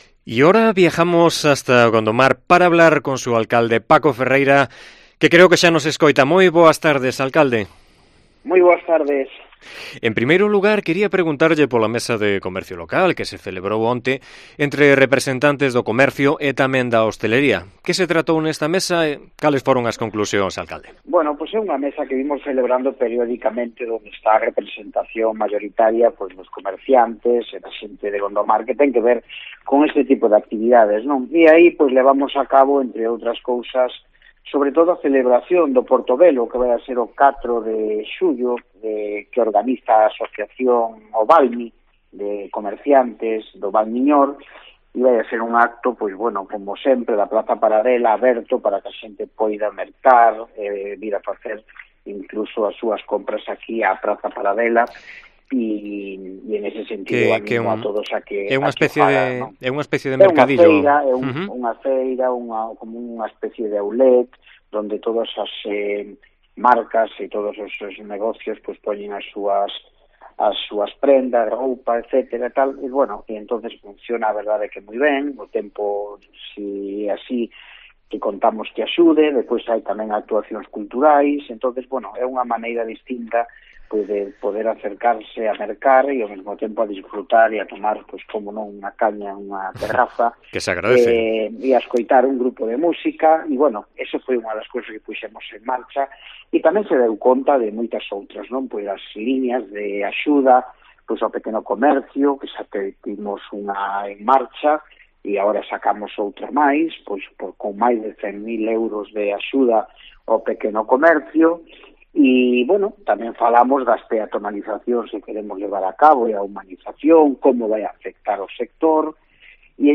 Entrevista a Paco Ferreira, alcalde de Gondomar